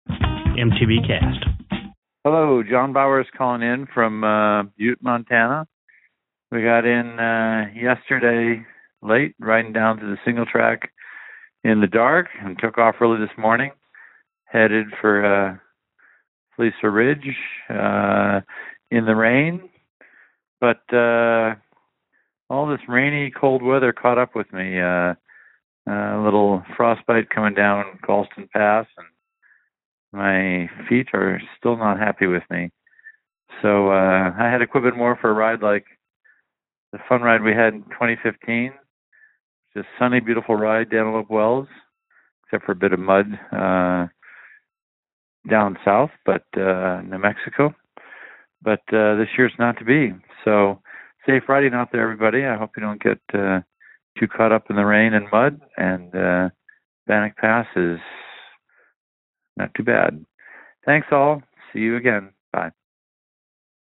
Posted in Calls , TD18 Tagged bikepacking , cycling , MTBCast , TD18 , ultrasport permalink